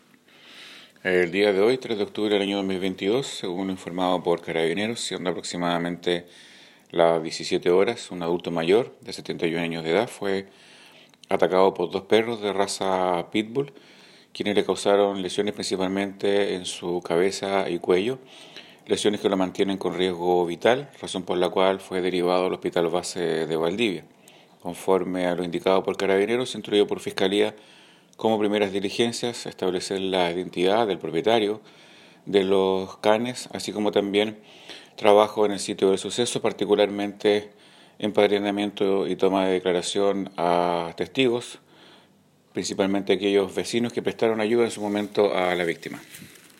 Fiscal Raúl Suárez sobre la investigación que dirige la Fiscalía de La Unión por las lesiones provocadas a un adulto mayor por dos perros pitbull, que lo mantienen con riesgo vital.